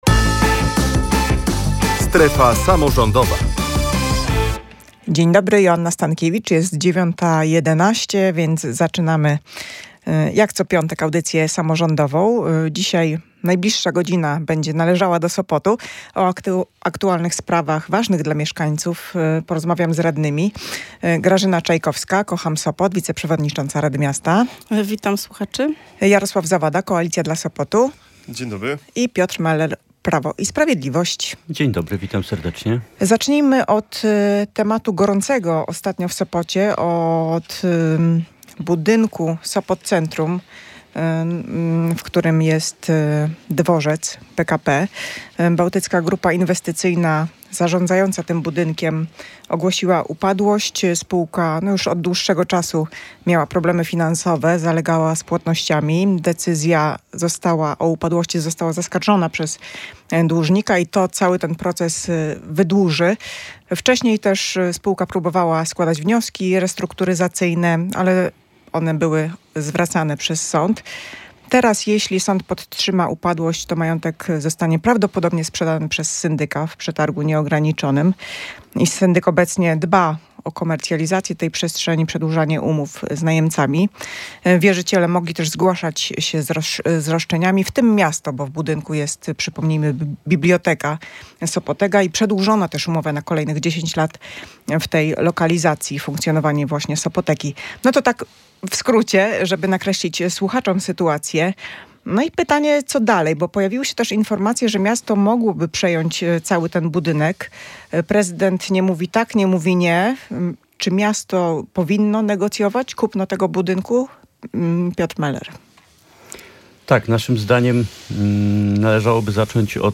Nie wiadomo, czy miasto byłoby stać na utrzymanie tak dużego obiektu jak Sopot Centrum - twierdzą radni miasta, którzy o